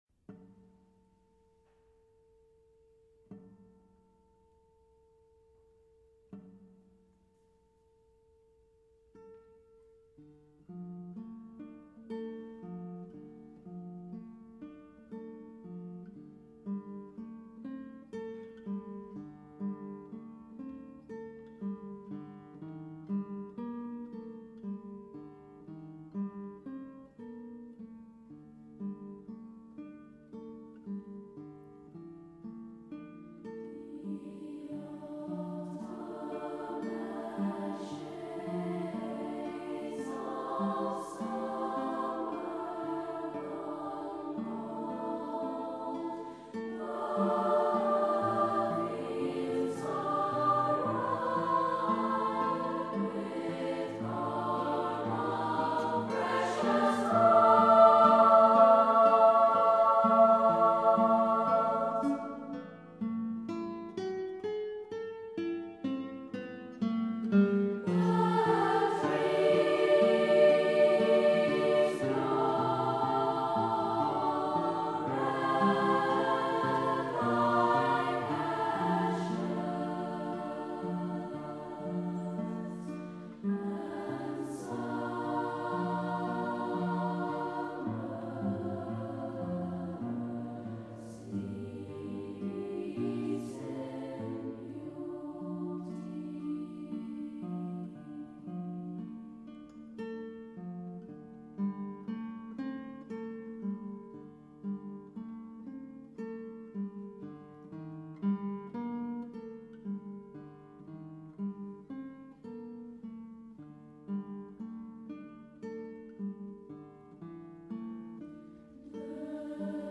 Tonalité : ré mineur